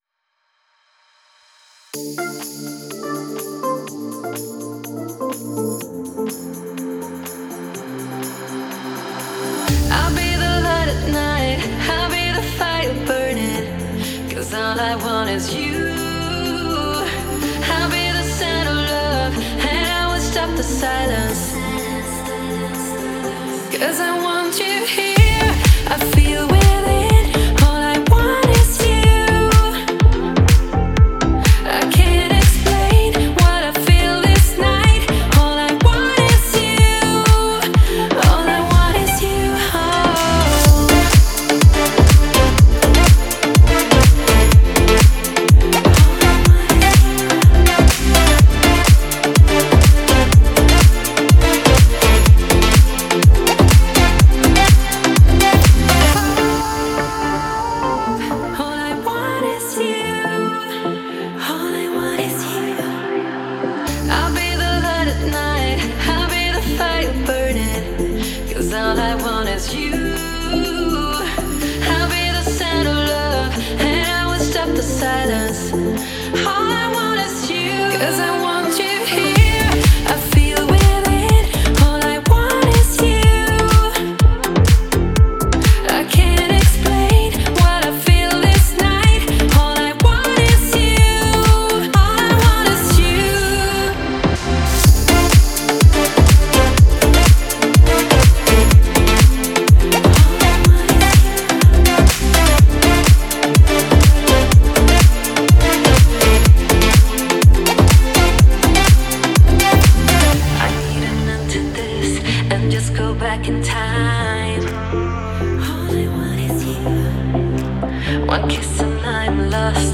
энергичная и мелодичная трек шведской группы